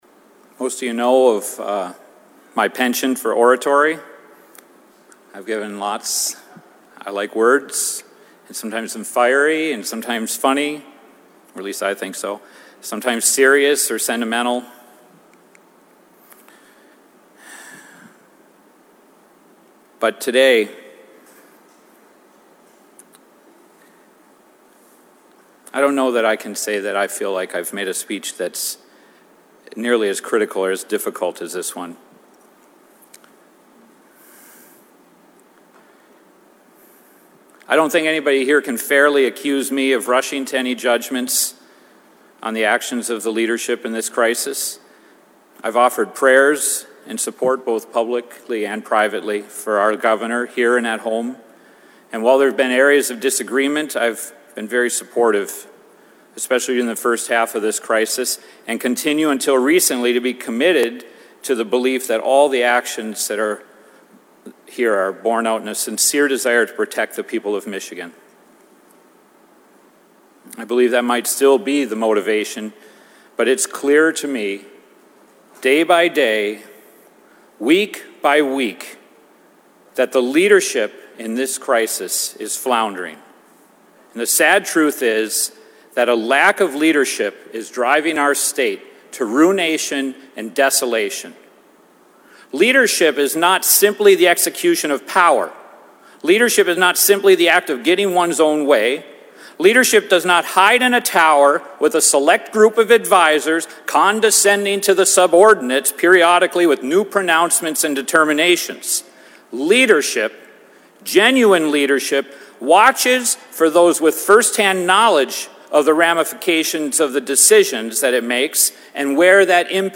Upper Peninsula State Senator Ed McBroom gave an emotional speech on the floor of the Michigan Senate Thursday afternoon, imploring Governor Gretchen Whitmer to ease restrictions on life, snd business, in the U.P.
“I have a 103-year-old farm, and five generations,” McBroom said, his voice breaking.